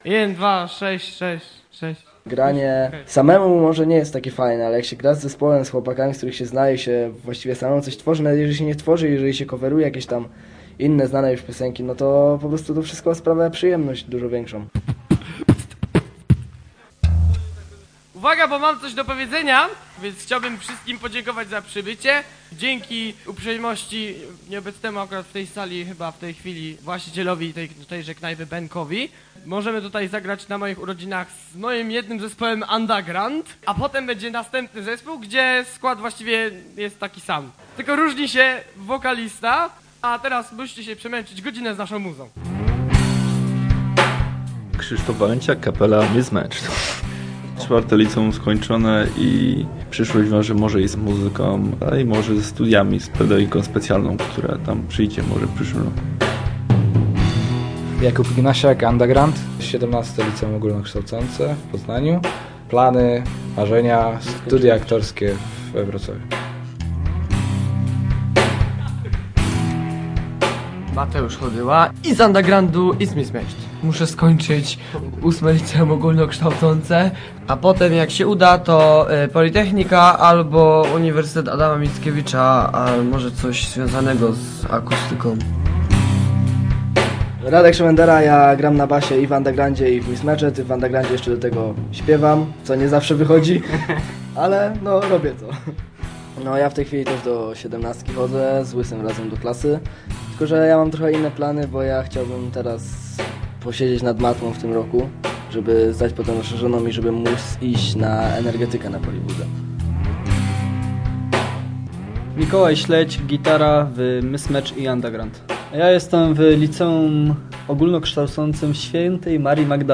Rockowa impresja - reportaż